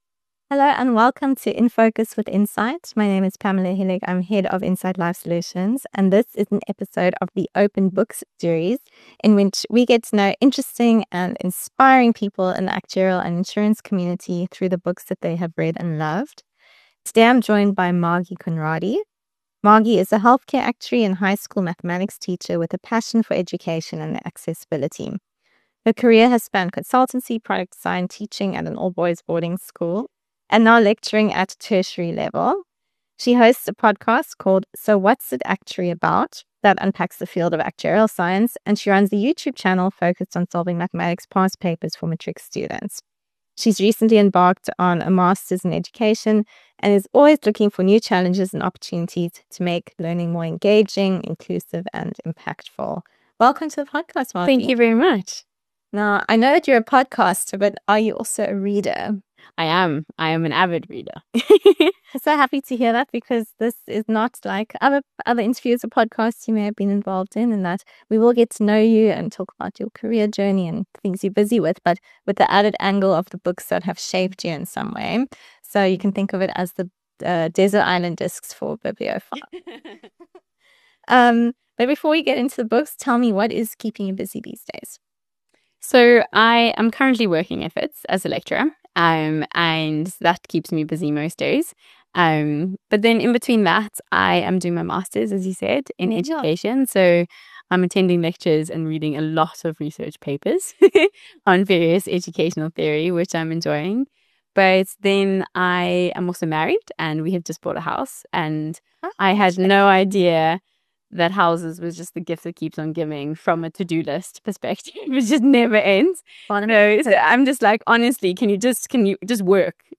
Gain invaluable insights into closing gaps, setting benchmarks, and leveraging unbiased data to spearhead meaningful changes in healthcare standards. Tune in for an illuminating conversation shaping the future of healthcare quality.